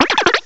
cry_not_scatterbug.aif